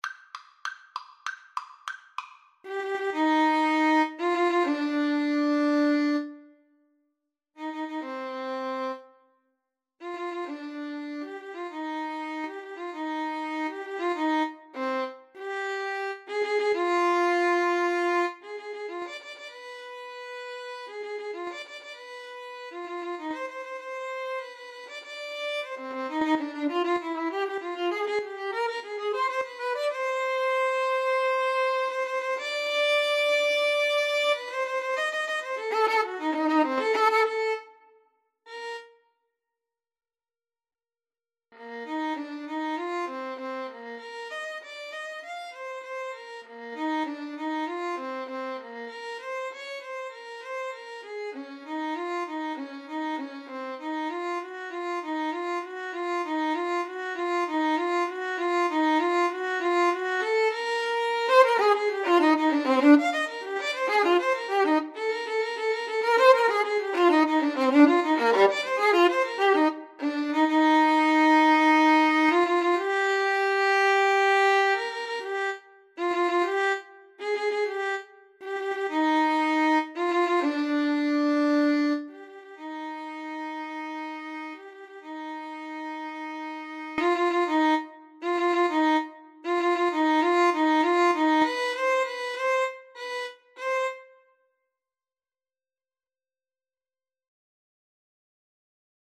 2/4 (View more 2/4 Music)
Allegro con brio (=108) =98 (View more music marked Allegro)
Classical (View more Classical Violin-Cello Duet Music)